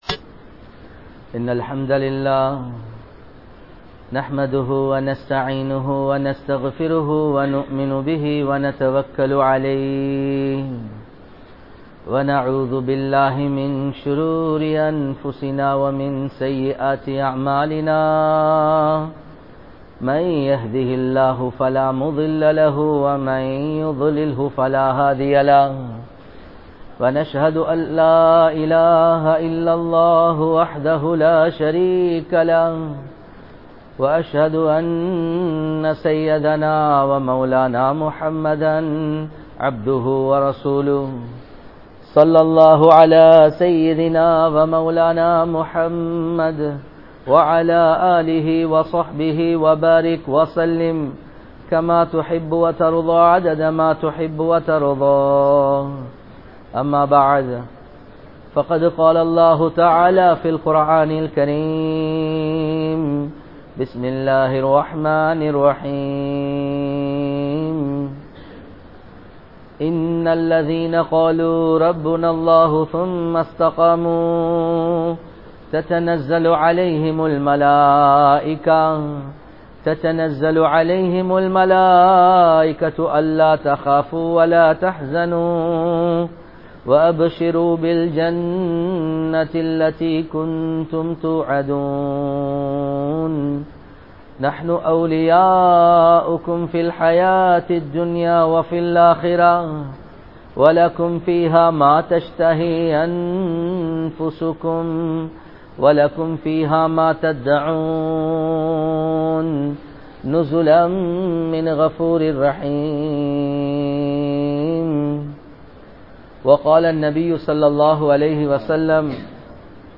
Allah`vukkaaha Thiyaaham Seiungal (அல்லாஹ்வுக்காக தியாகம் செய்யுங்கள்) | Audio Bayans | All Ceylon Muslim Youth Community | Addalaichenai
Addalaichenai 03, Grand Jumua Masjith